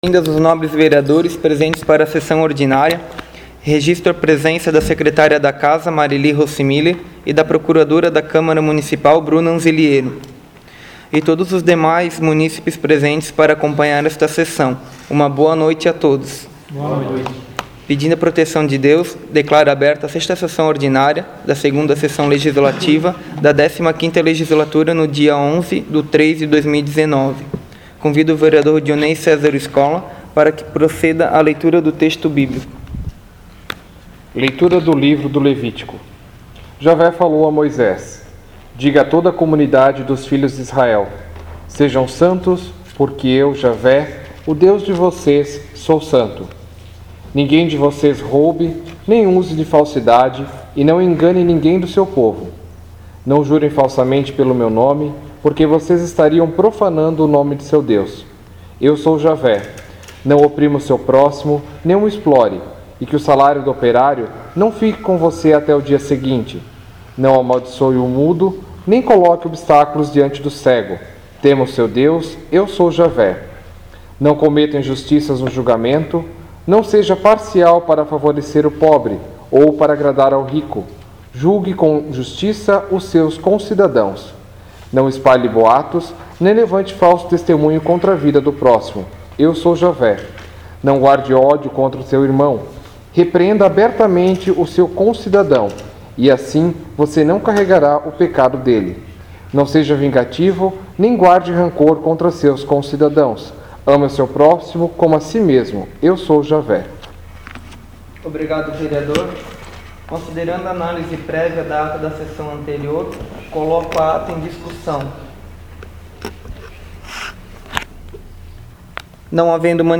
Áudio da 6ª Sessão Ordinária de 2019